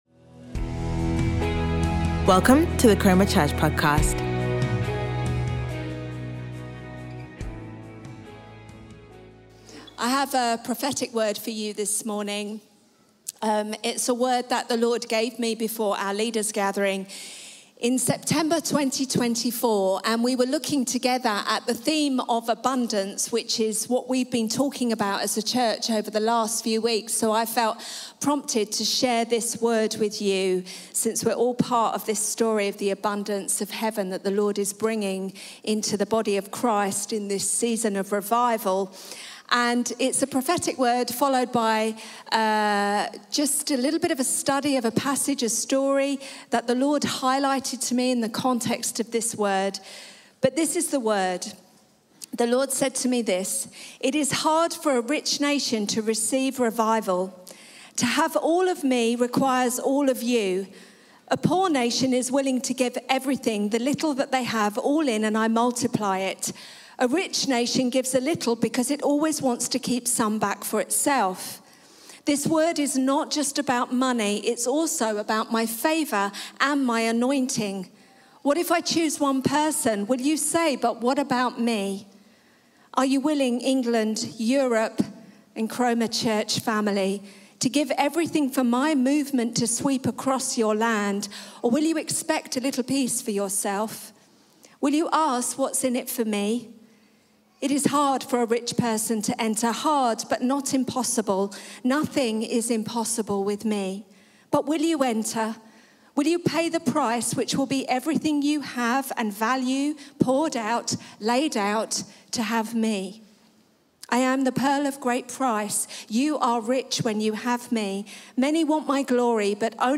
Chroma Church Live Stream
Sunday Sermon